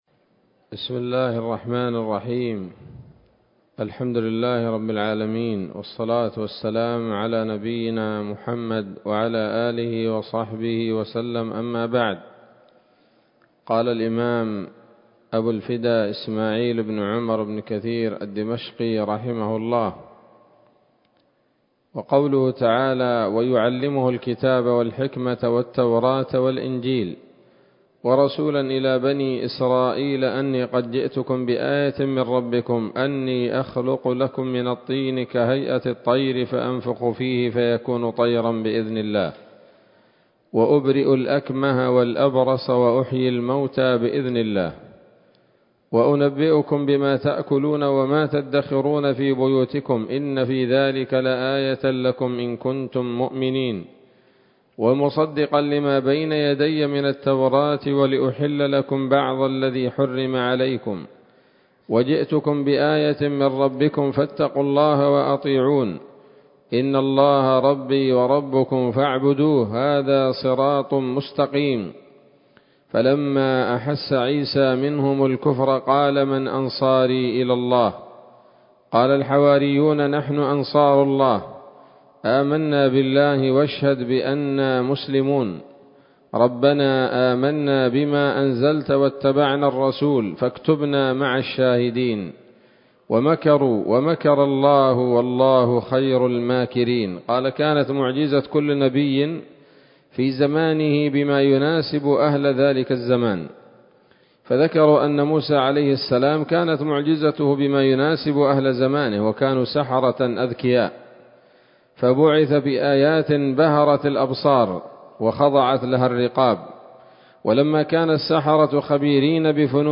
‌‌الدرس الثامن والأربعون بعد المائة من قصص الأنبياء لابن كثير رحمه الله تعالى